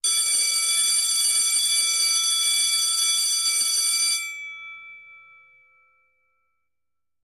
Bells; 10 School Bell.